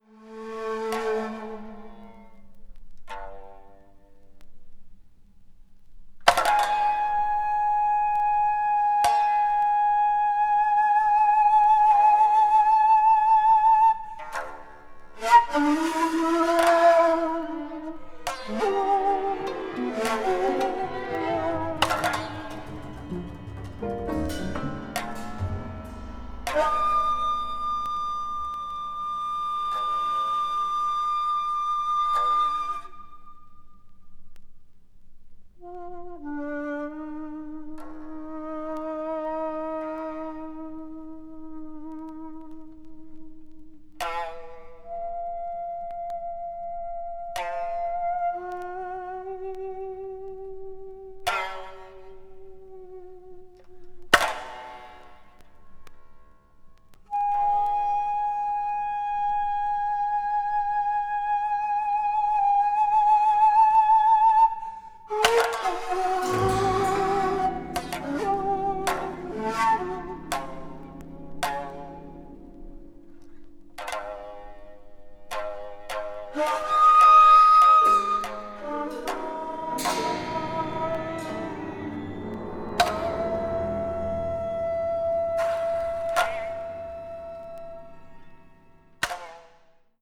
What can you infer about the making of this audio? media : EX/EX(some slightly surface noises.)